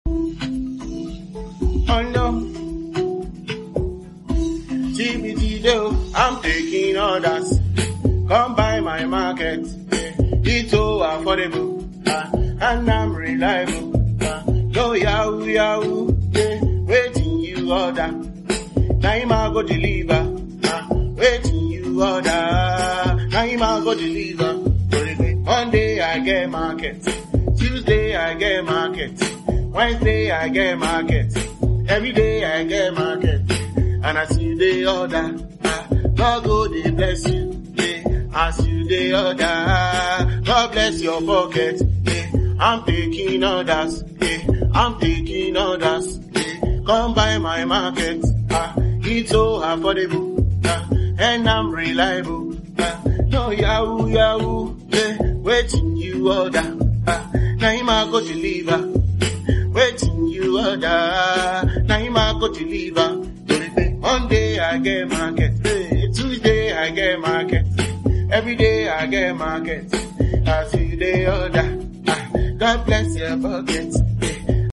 Hisense washing machine 8Kg for sound effects free download